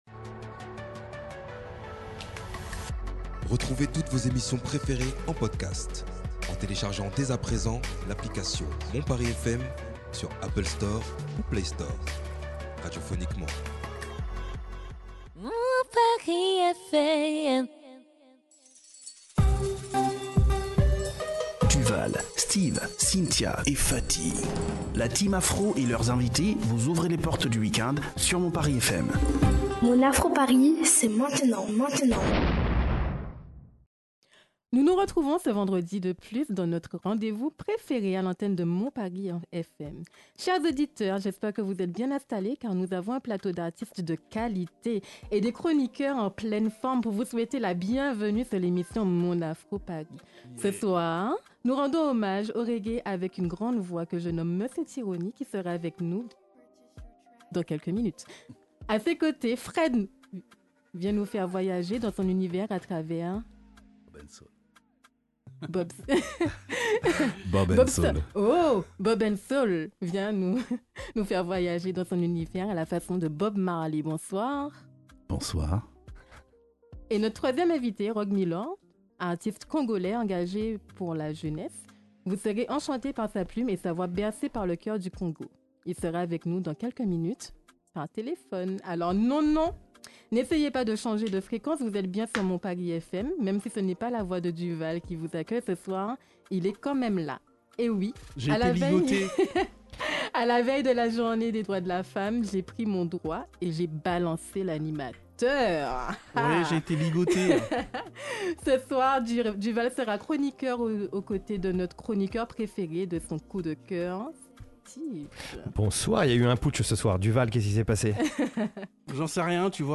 07 mars 2025 - 19:18 Écouter le podcast Télécharger le podcast En prélude à journée internationale des droits des femmes, l’heure de la révolte a sonné sur le plateau. C’est également la rencontre des caraïbes et de l’Afrique en musique.